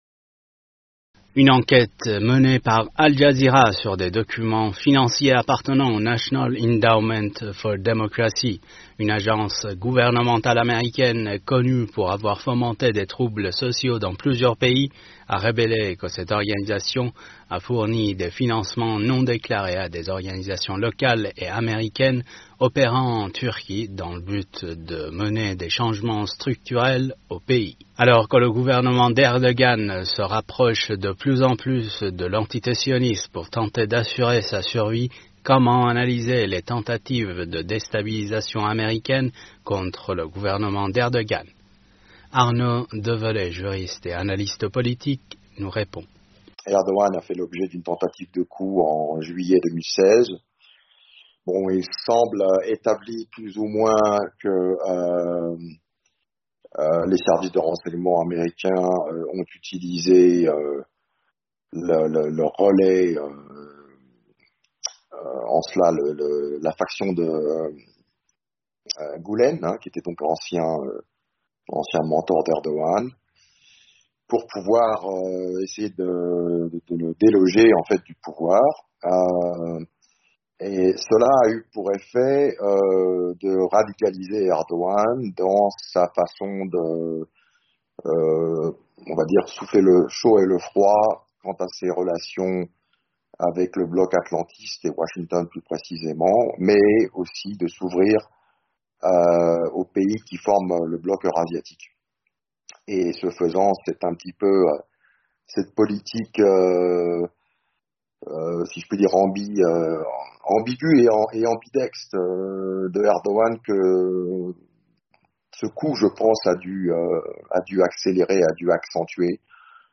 juriste international, s’exprime sur le sujet.